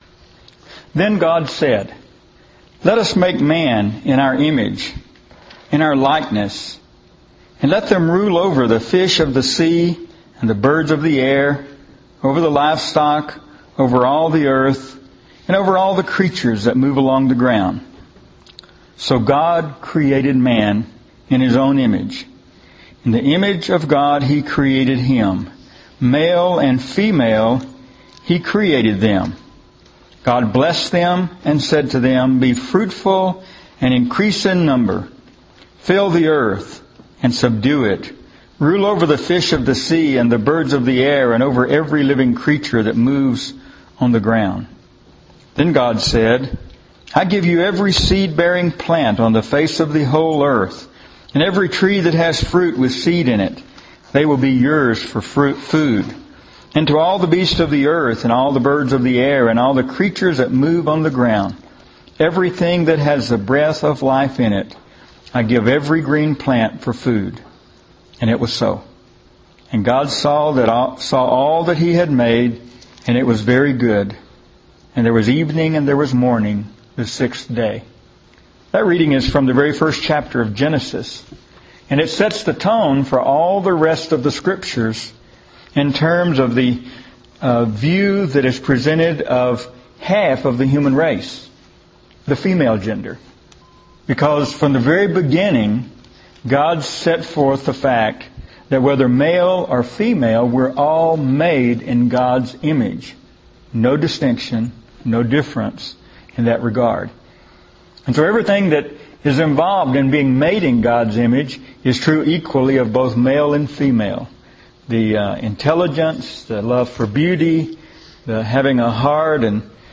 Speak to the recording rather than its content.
before an audience at the Tanglewood Church of Christ in Odessa, Texas in 2009